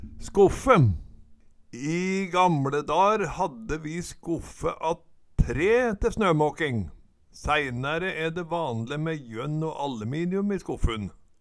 skoffe - Numedalsmål (en-US)